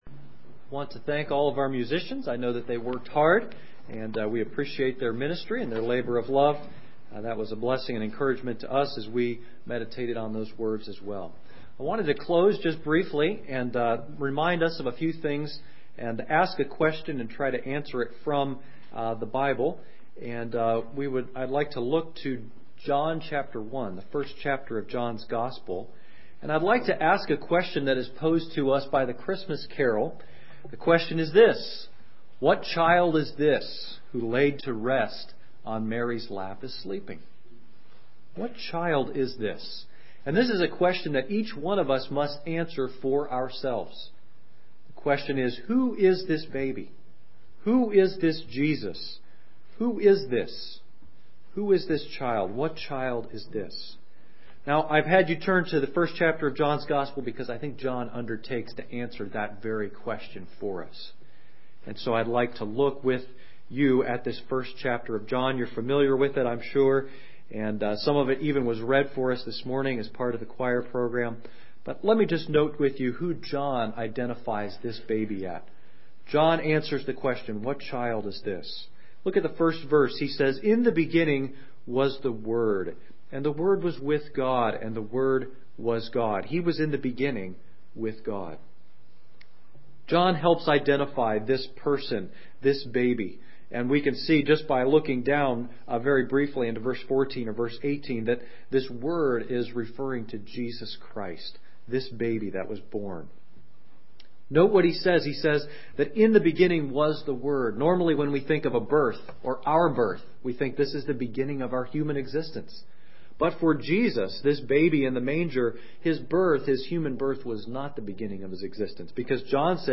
Service Sunday Morning